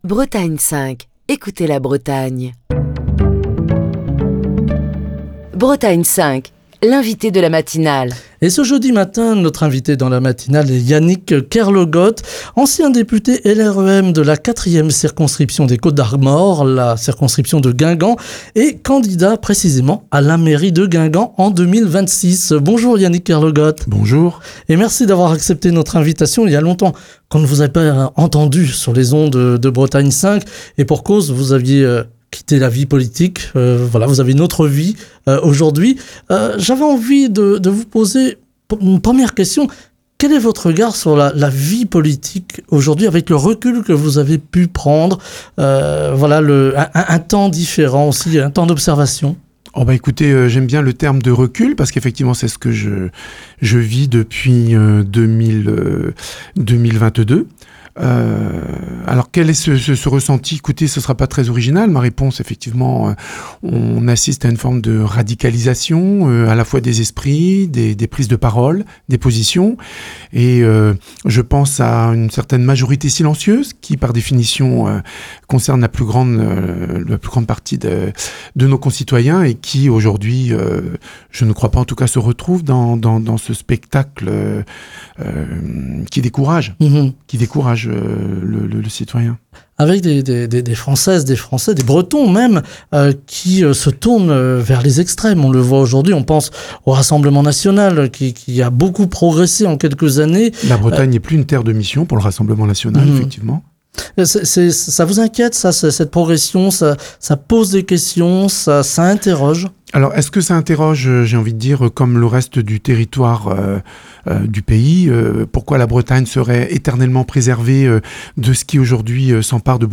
Yannick Kerlogot, ancien député LREM de la 4ème circonscription des Côtes-d'Armor et candidat déclaré à la mairie de Guingamp pour les municipales de 2026, était ce jeudi l'invité politique de la matinale de Bretagne 5.